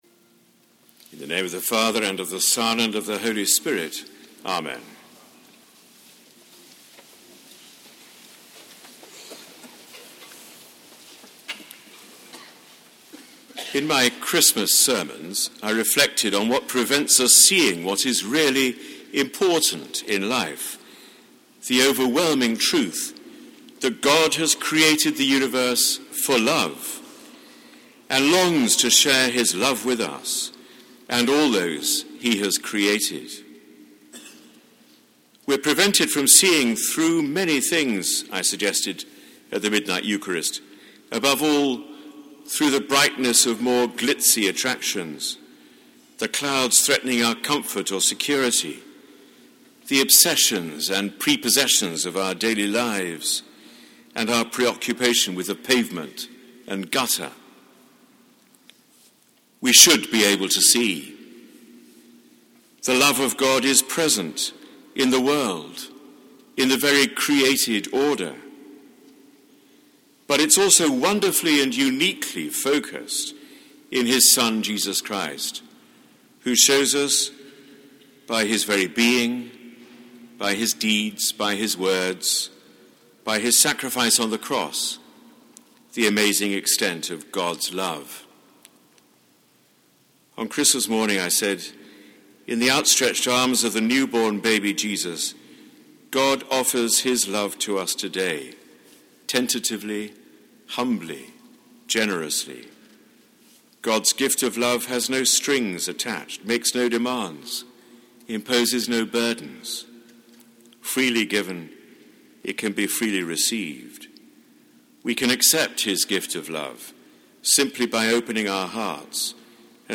Sermon given on the Feast of the Epiphany: Thursday 6 January 2011
Sermon given by the Very Reverend Dr John Hall, Dean of Westminster. Today’s feast offers us an opportunity to renew our commitment to receive afresh the grace and power of God through his Word and in his Sacraments so that we can more effectively share his love with others.